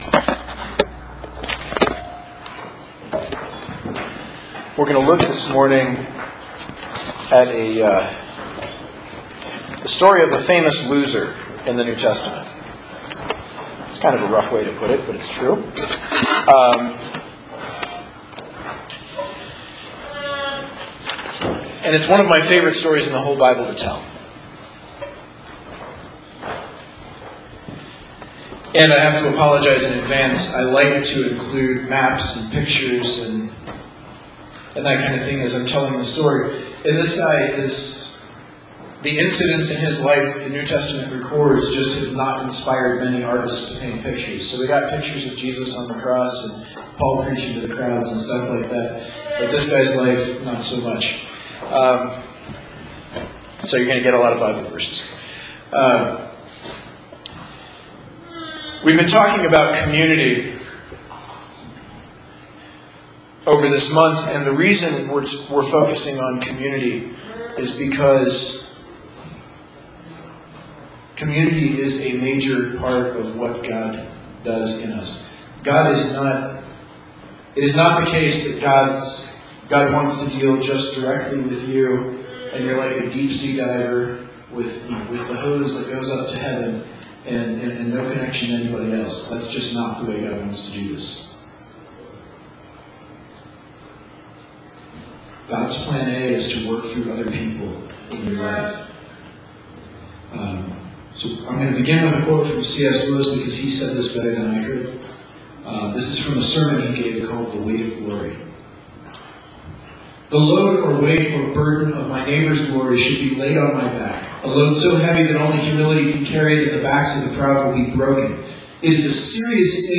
I had occasion to tell the story of John Mark in a church service recently.